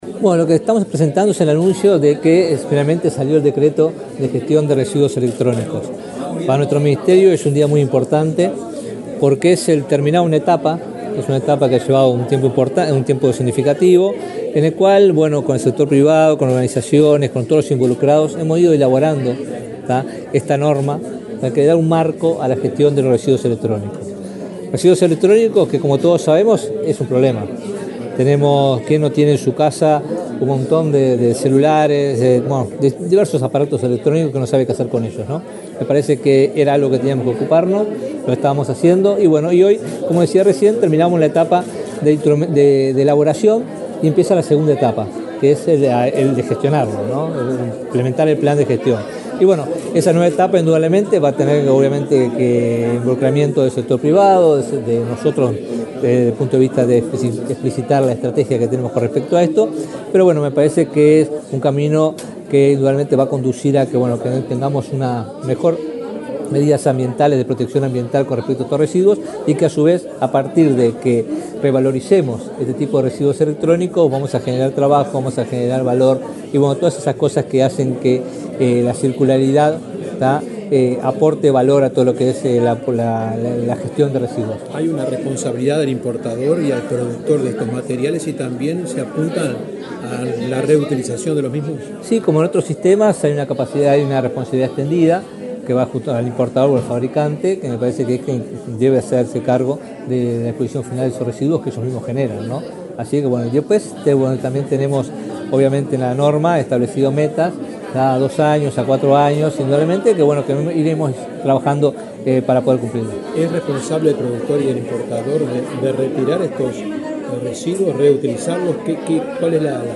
Declaraciones del ministro de Ambiente, Robert Bouvier
Declaraciones del ministro de Ambiente, Robert Bouvier 12/11/2024 Compartir Facebook X Copiar enlace WhatsApp LinkedIn Este martes 12 en Montevideo, el ministro de Ambiente, Robert Bouvier, presentó el decreto de reglamentación de la gestión de residuos eléctricos y electrónicos. Luego explicó a la prensa el alcance de la mencionada norma.